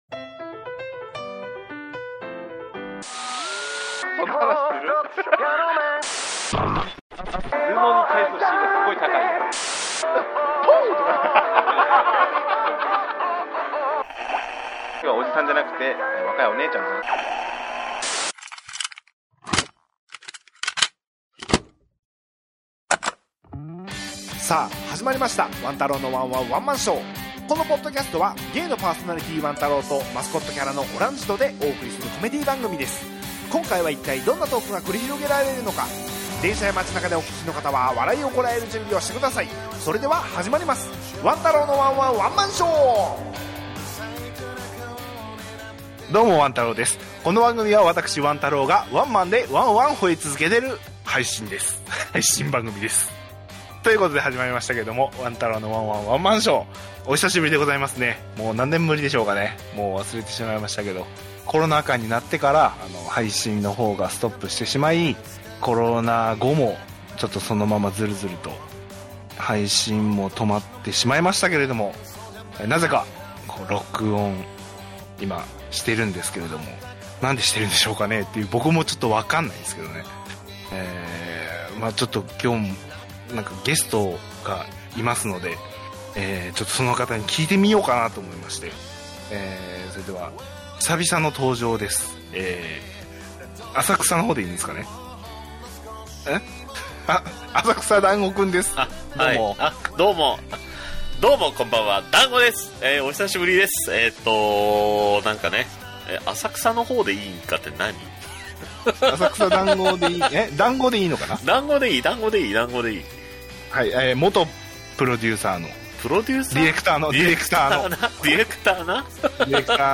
お聴きづらくて申し訳ありません。